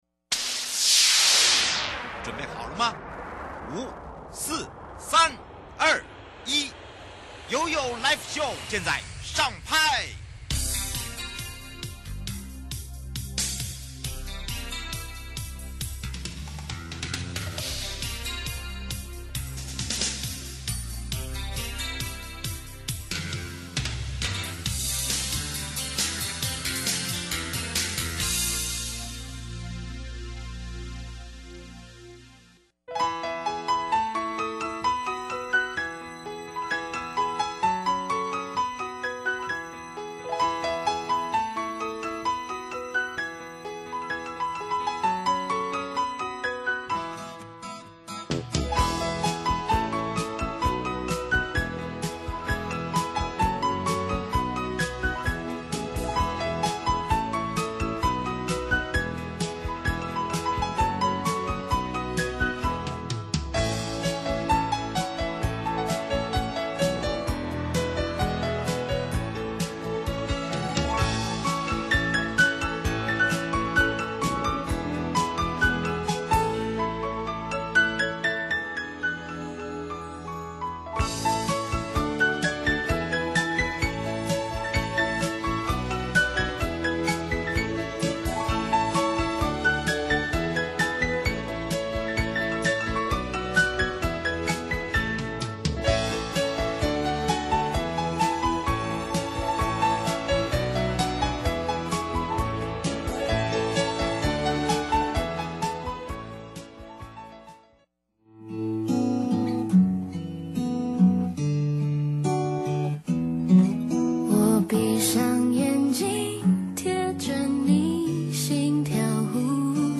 受訪者： 1.台北地檢王銘裕主任檢察官 2.台北地檢周章欽檢察長 節目內容： 1.